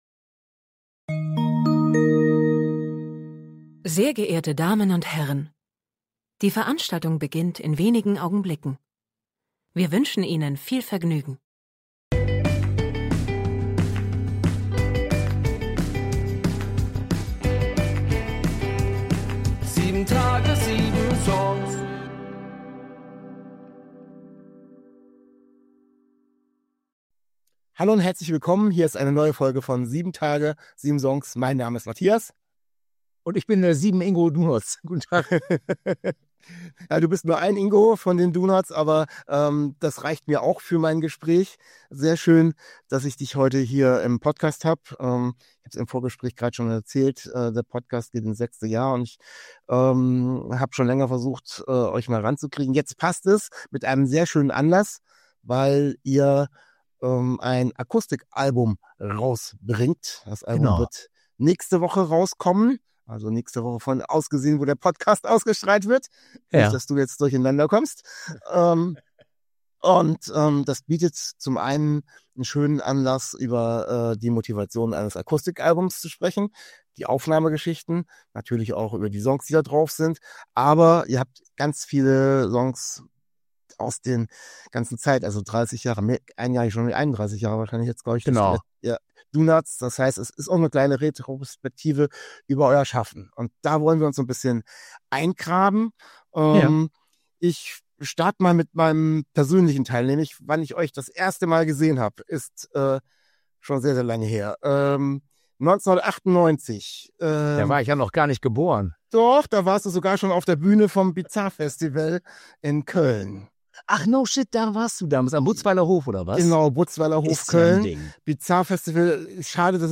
Zu Gast ist Ingo, Frontman von de DONOTS. Wir sprechen über mehr als 30 Jahre Bandgeschichte und über das neue Akustik Album.